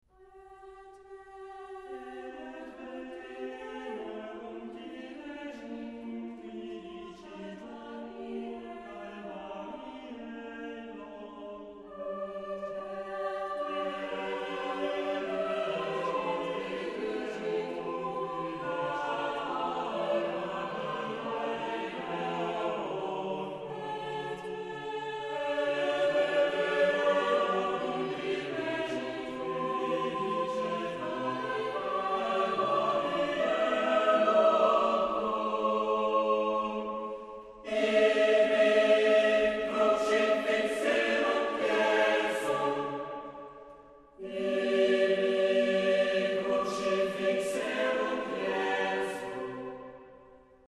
Genre-Stil-Form: geistlich ; Motette
Chorgattung: SATB  (4-stimmiger gemischter Chor )
Tonart(en): F-Dur
Lokalisierung : JS-19e Sacré A Cappella